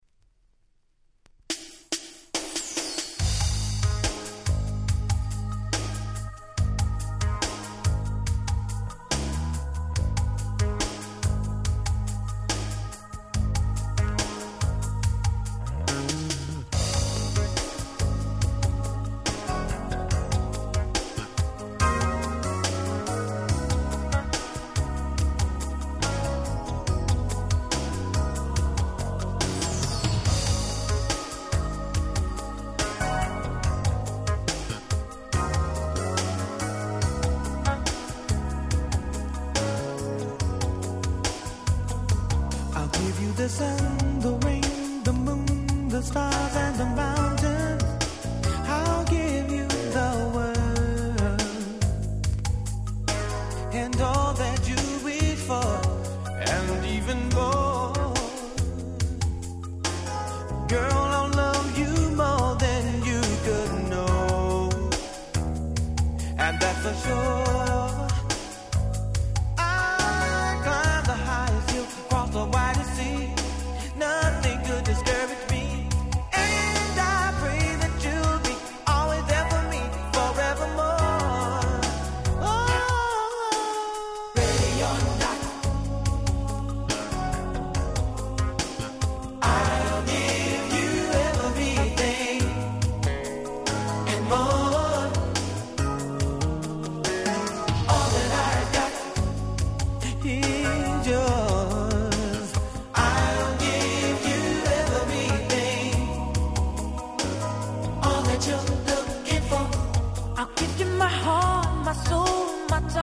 89' Big Hit R&B LP !!